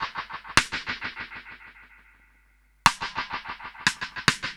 Index of /musicradar/dub-drums-samples/105bpm
Db_DrumsA_SnrEcho_105_01.wav